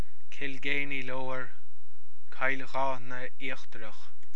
Placename : Kilgainy Lower In Irish : Cill Gháithne Íochtarach Administrative Unit : Townland Pronunciation Audio File Administrative Details: Barony : Upperthird Parish : St. Mary's, Clonmel Old Irish Typeface